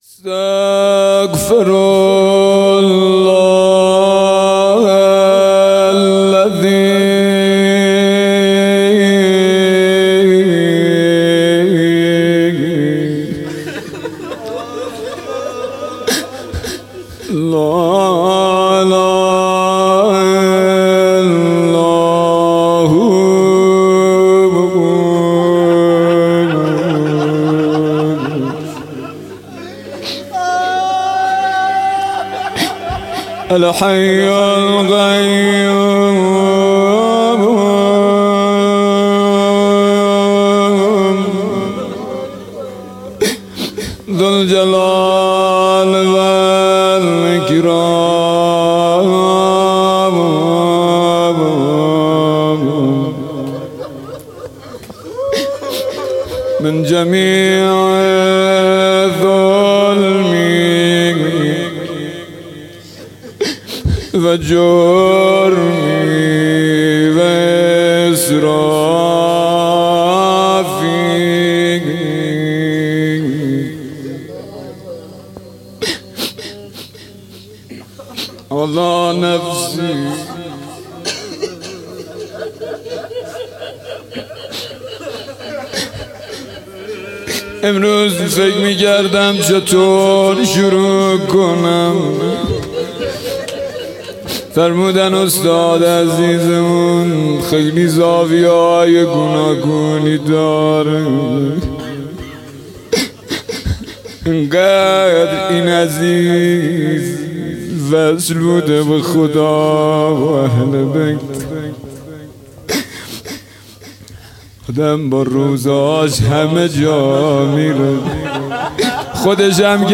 سالگرد حاج قاسم سلیمانی
روضه